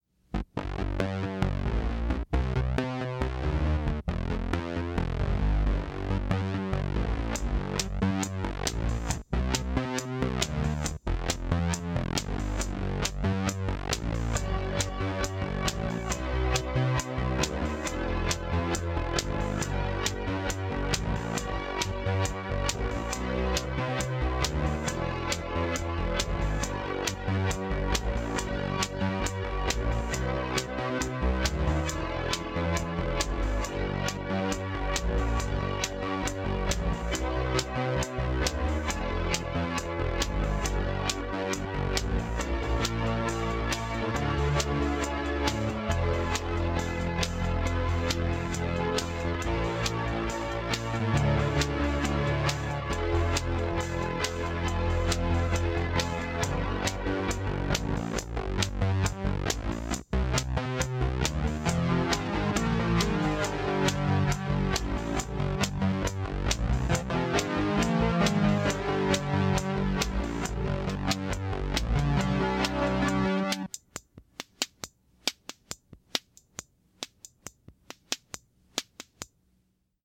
Instrumental tracks for "Momentum."